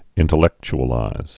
(ĭntl-ĕkch-ə-līz)